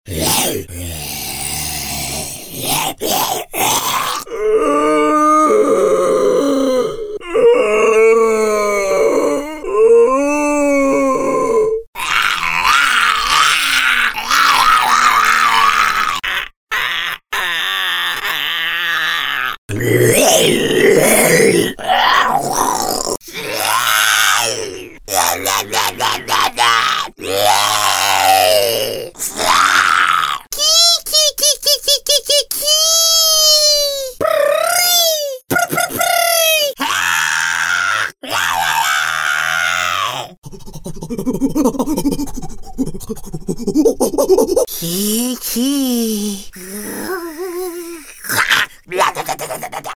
creatures